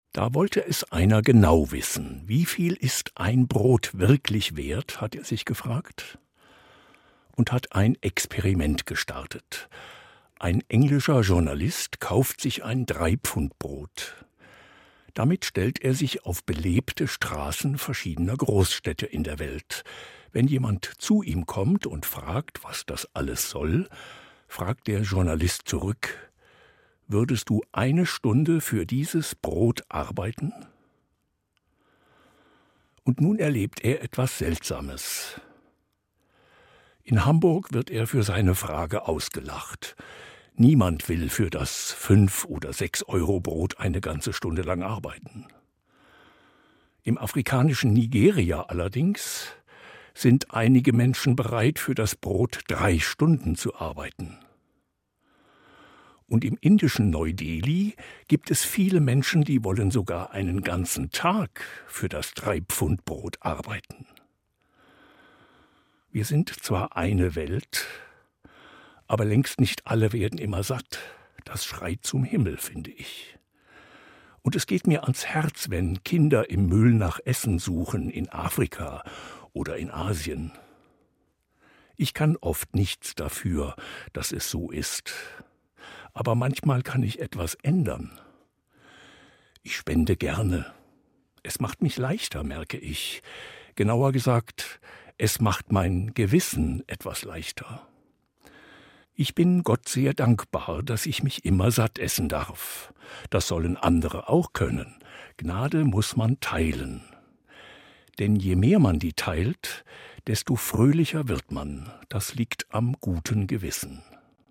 Eine Sendung von
Evangelischer Pfarrer, Kassel